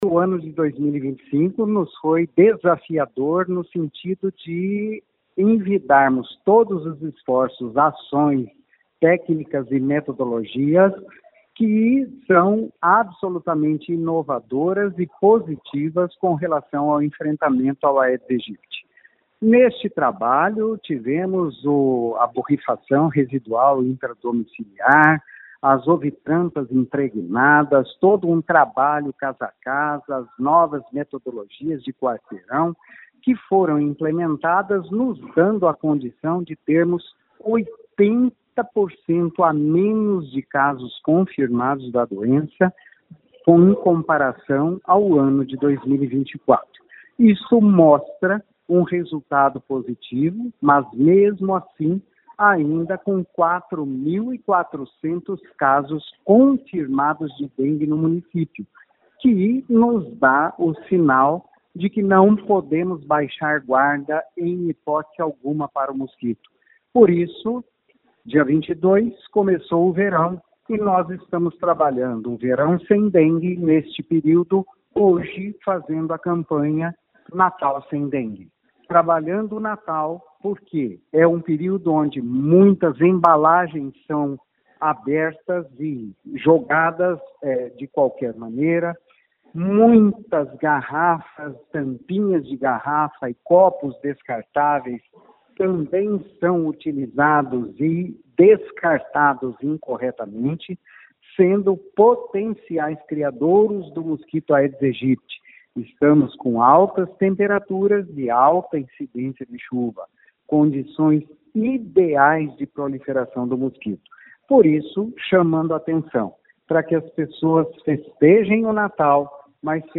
Ouça o que diz o secretário Antônio Carlos Nardi.